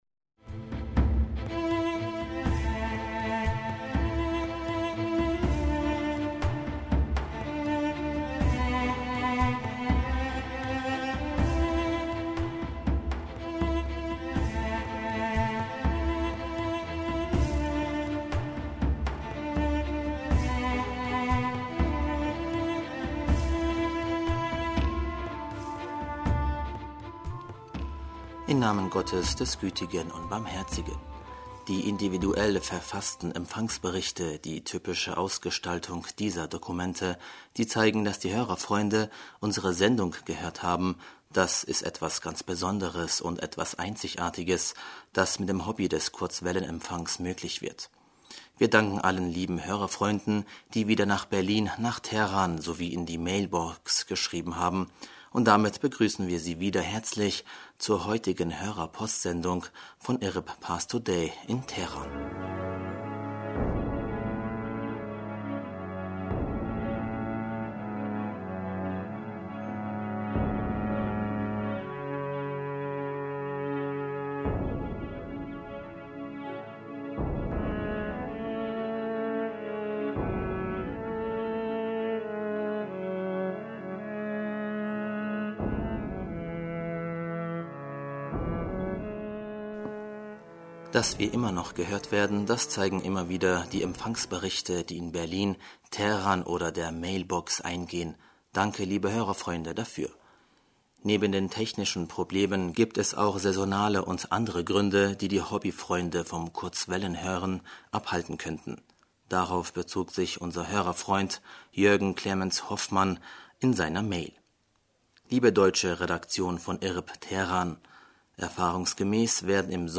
Hörerpostsendung am 08. Juli 2018 - Bismillaher rahmaner rahim - Die individuell verfassten Empfangsberichte – die typische Ausgestaltung dieser D...